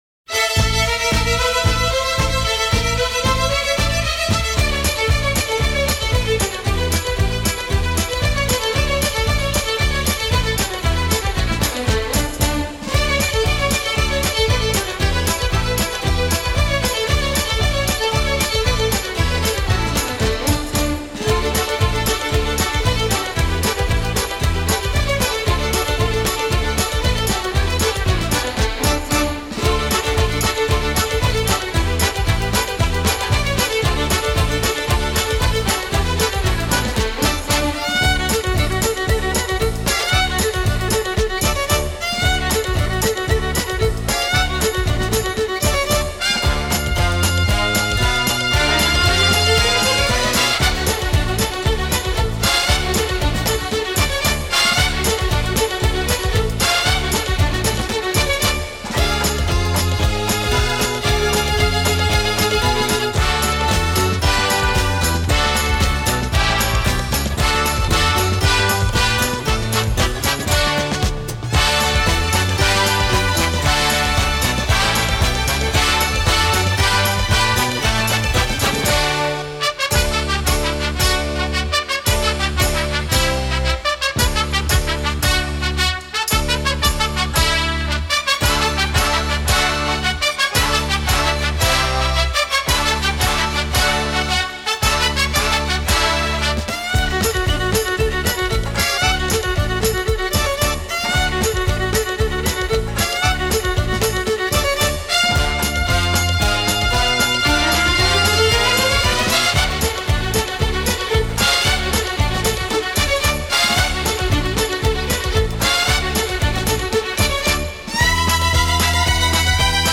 (Square Dance)